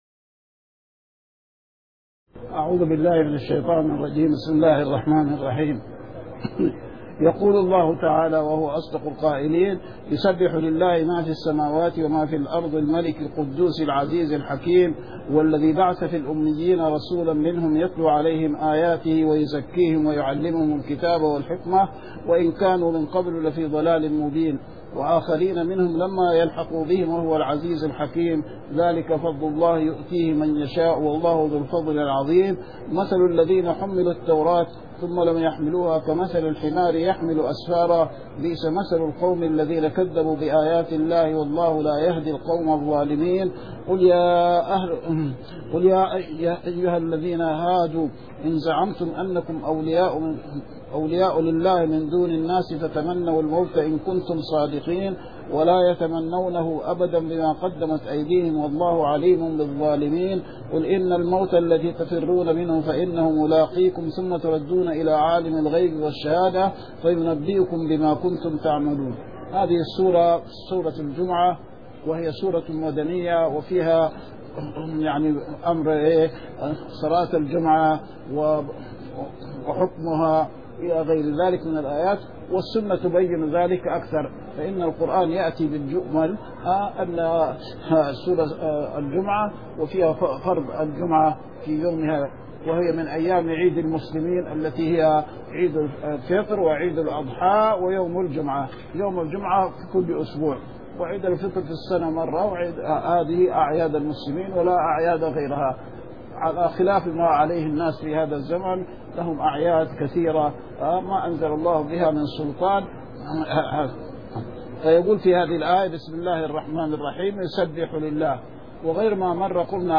من دروس الحرم المدنى الشريف